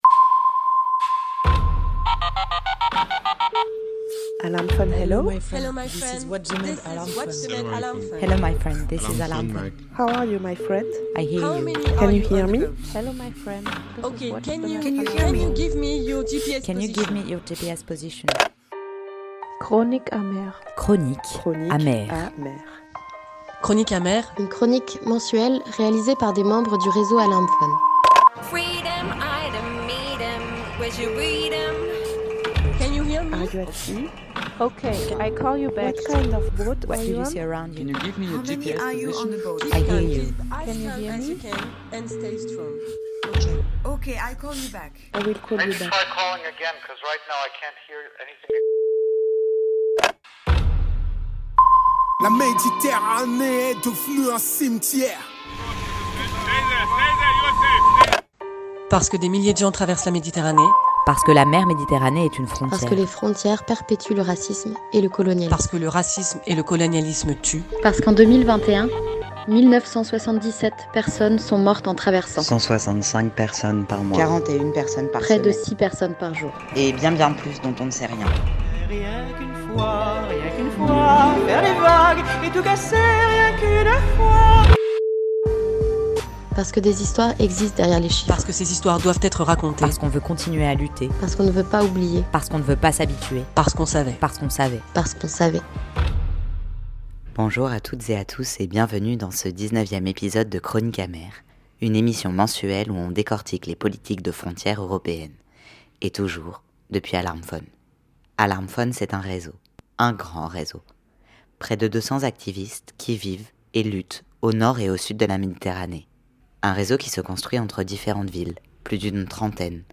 Une discussion